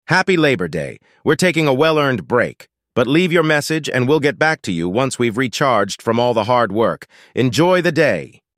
Holiday voicemail greetings
Labour-day-voicemail.mp3